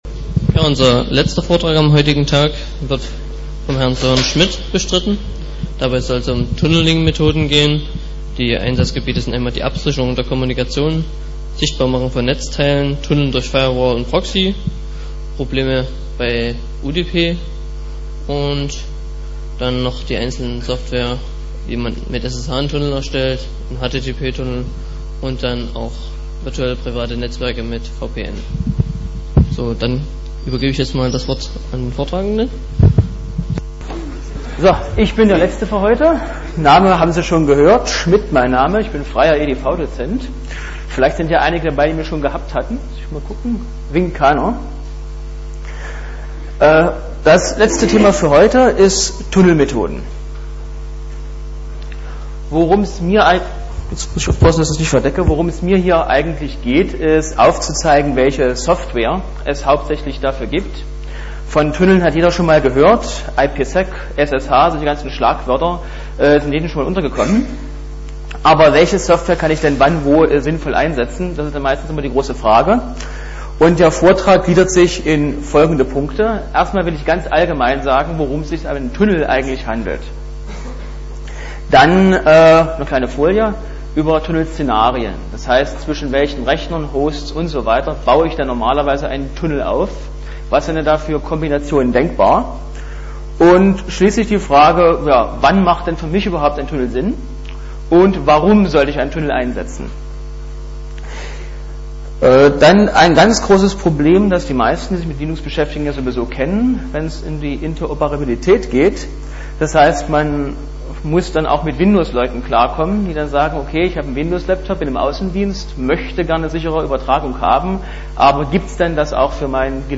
Der 5. Chemnitzer Linux-Tag ist eine der größten Veranstaltungen seinder Art in Deutschland.
Vortragsmittschnitt